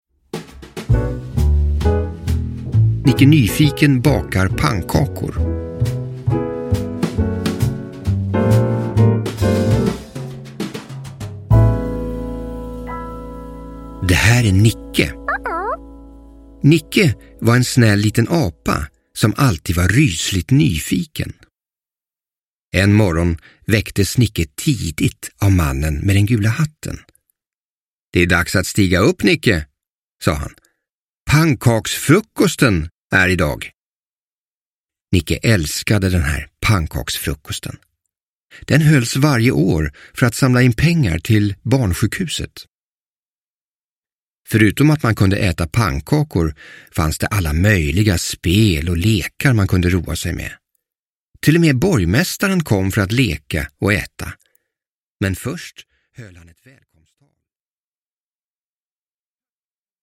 Nicke Nyfiken bakar pannkakor – Ljudbok – Laddas ner